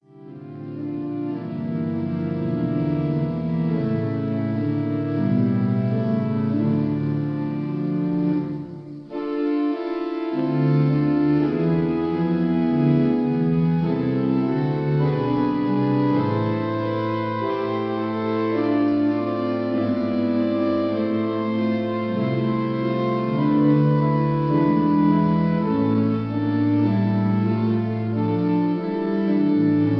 Organ of Église Sainte-Aurélie
Strasbourg